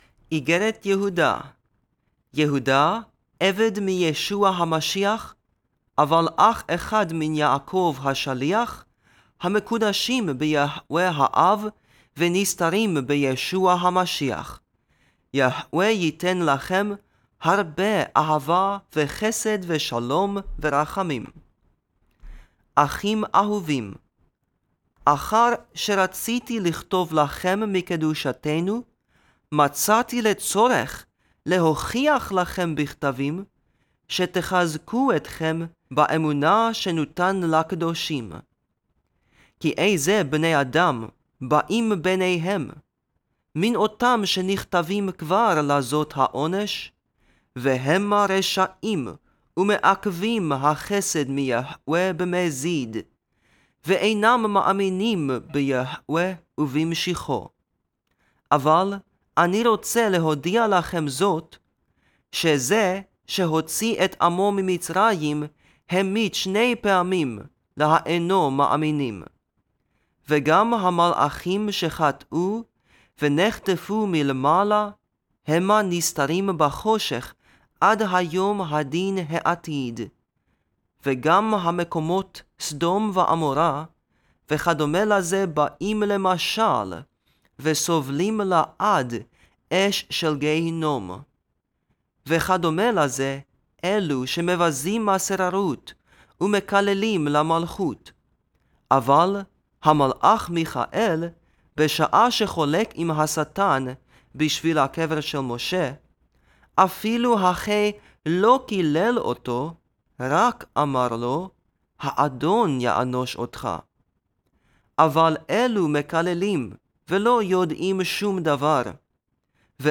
The pronunciation used is modern Hebrew (Except for the Name YHWH).